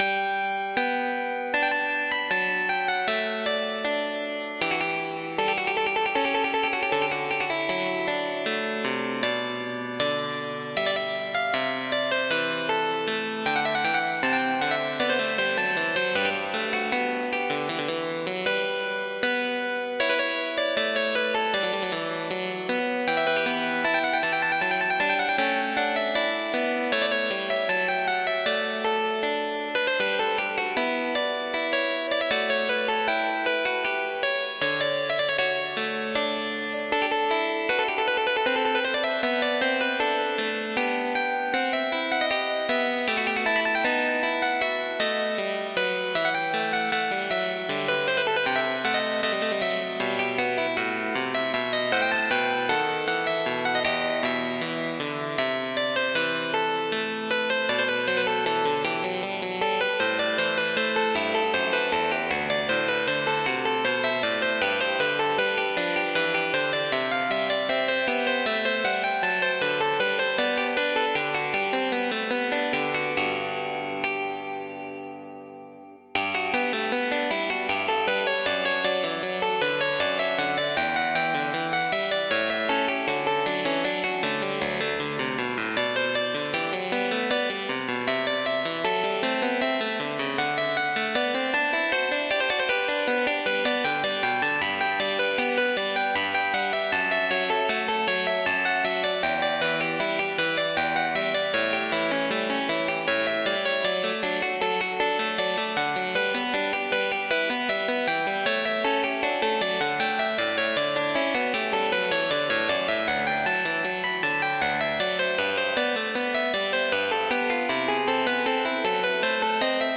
Barroco
polifonía
zarabanda
teclado
aria
clave
clavicordio
contrapunto
ornamentación
sintetizador